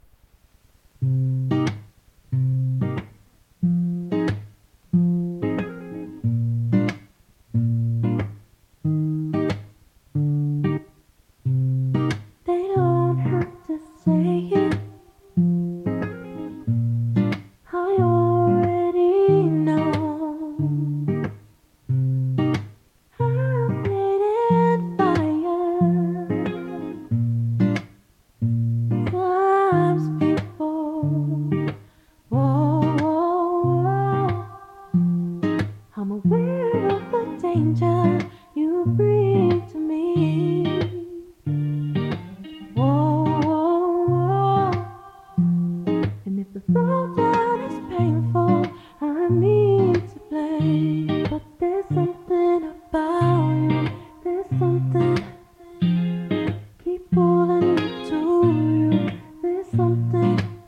Below is a playback of a recording made by the CS-703D:
Type: 2-head, single compact cassette deck
Track System: 4-track, 2-channel stereo